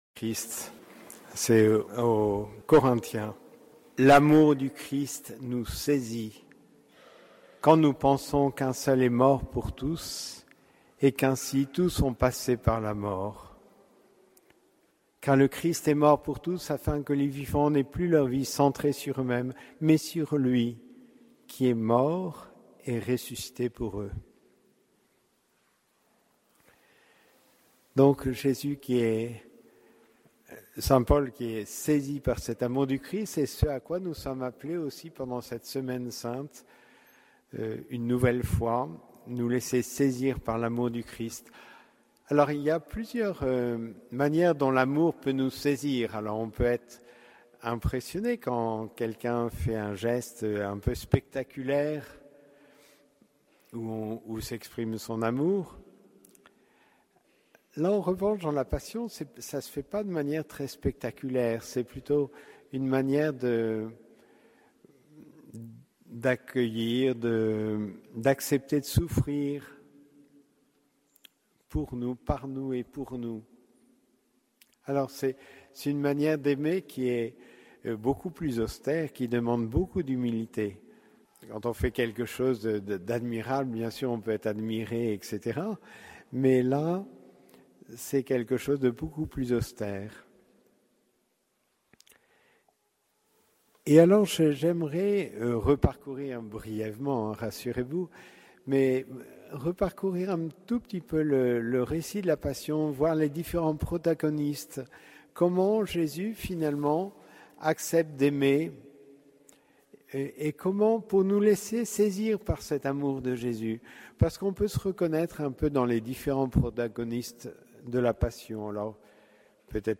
Homélie du dimanche des Rameaux et de la Passion
Une partie de l’enregistrement de l’homélie est manquant, veuillez nous en excuser…